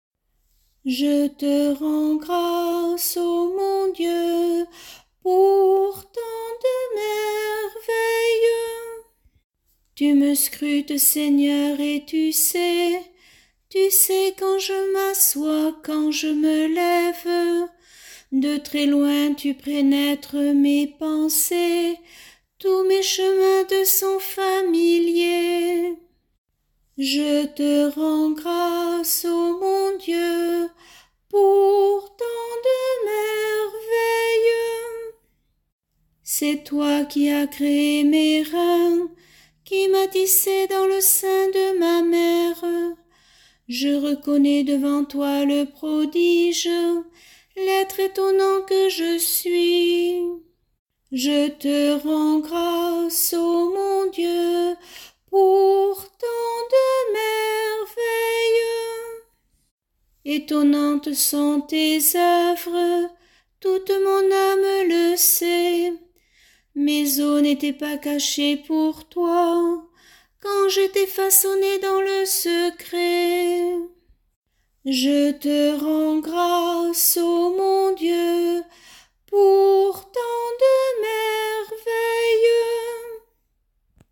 Chorale psaumes année A – Paroisse Aucamville Saint-Loup-Cammas